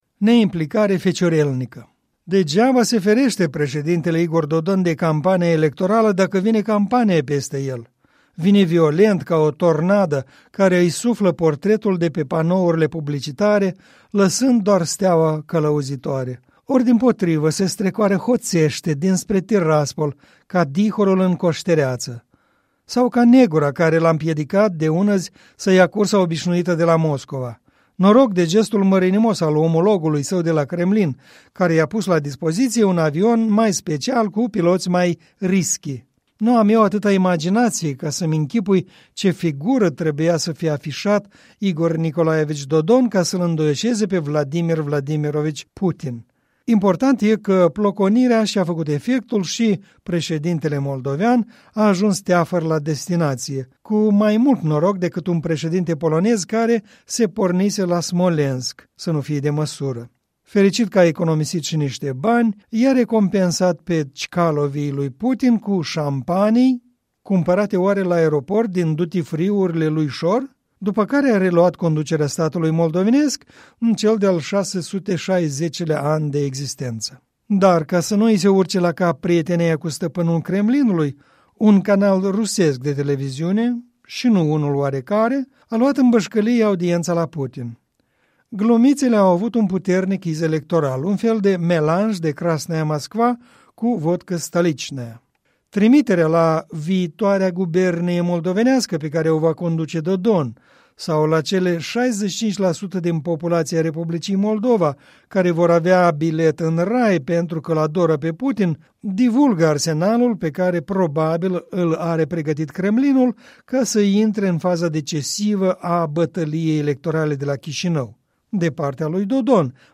Un minut și un comentariu electoral...